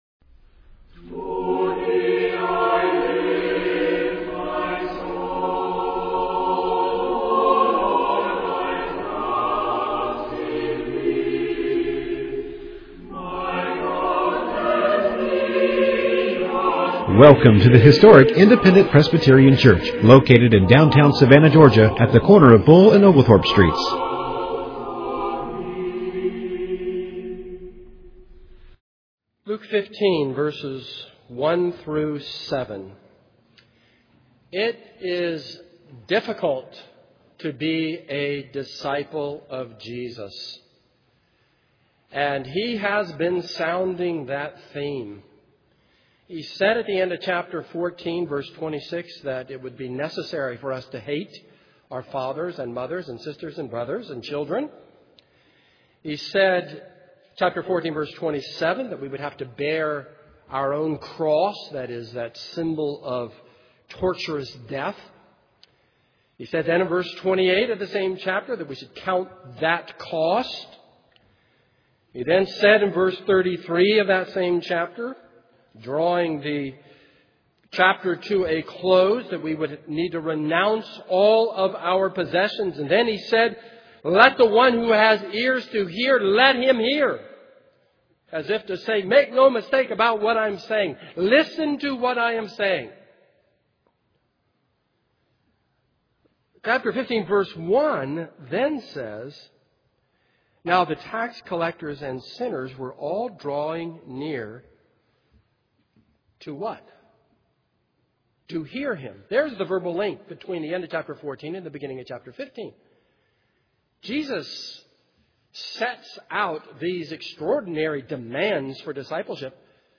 This is a sermon on Luke 15:1-7.